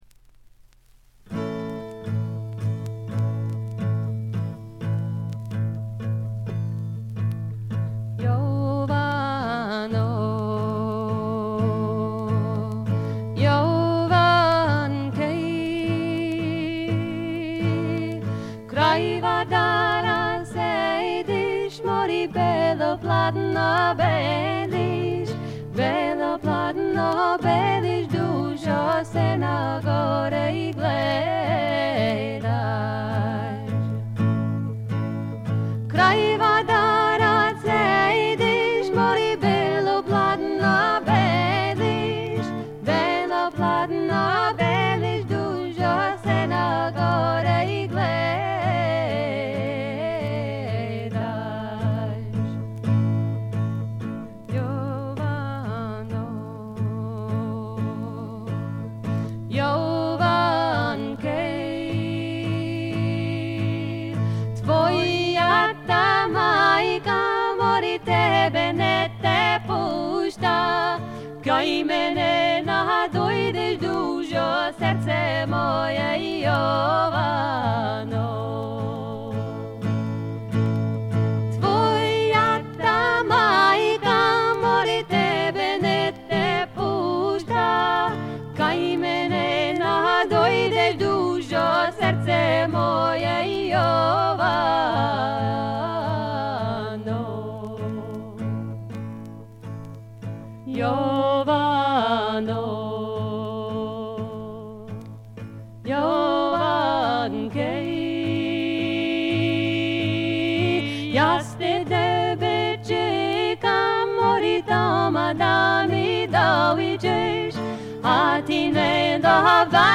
細かなバックグラウンドノイズやチリプチは多め大きめに出ますが鑑賞を妨げるほどではないと思います。
自主フォーク、サイケ・フォーク界隈でも評価の高い傑作です。
試聴曲は現品からの取り込み音源です。